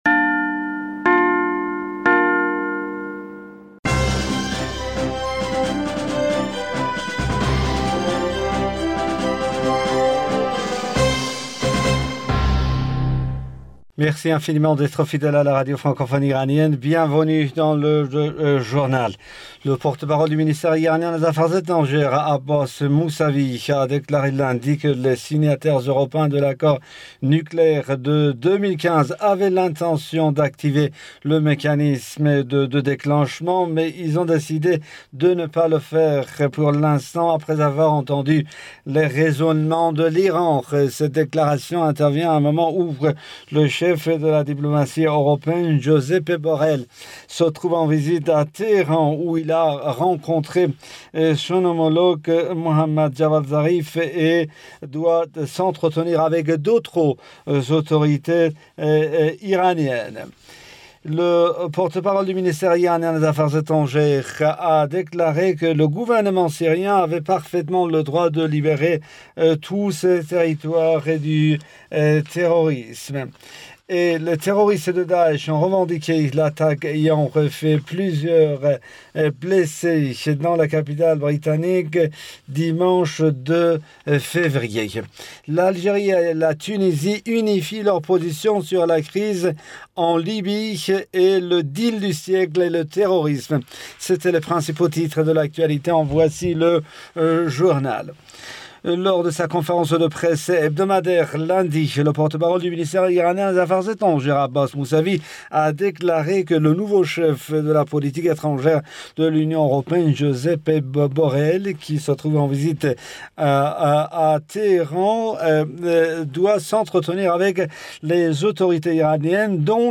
Bulletin d'information du 03 février 2020